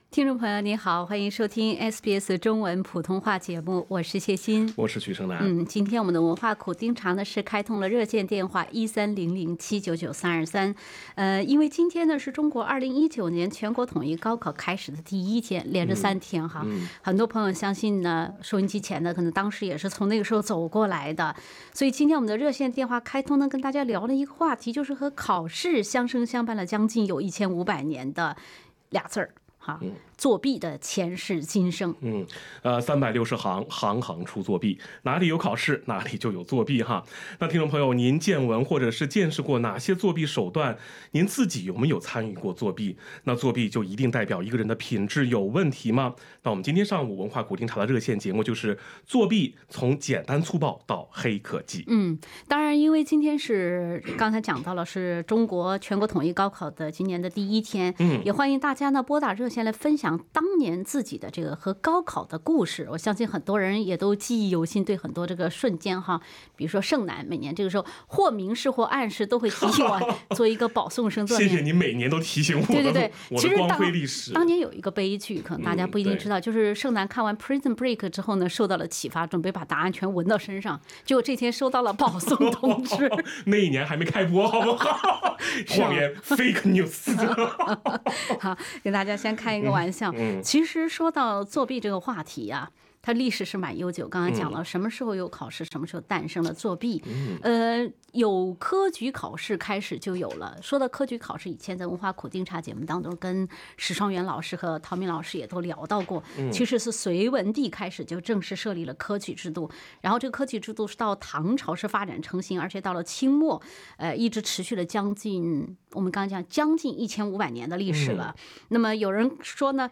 您听闻或见识过哪些作弊手段？自己有没有参与过作弊？作弊一定就代表一个人的品质有问题吗？文化苦丁茶热线节目--作弊，从简单粗暴到"黑科技"。